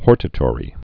(hôrtə-tôrē)